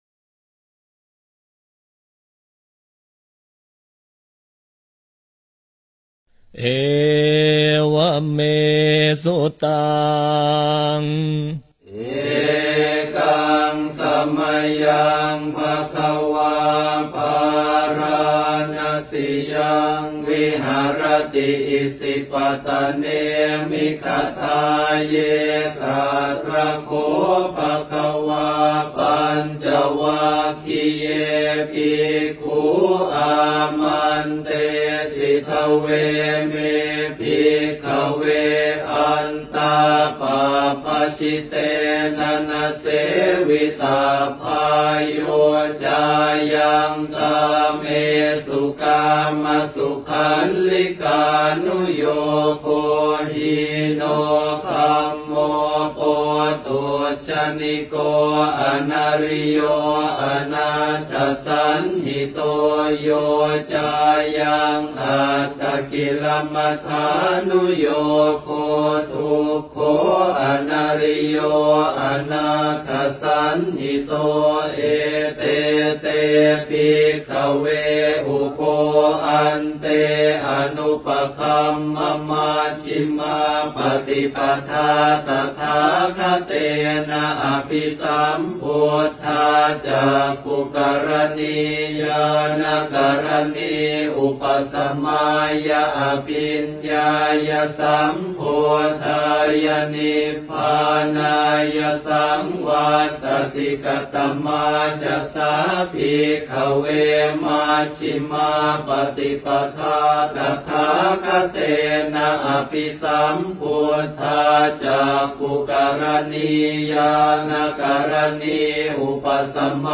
Suttas to be chanted / 诵念的经文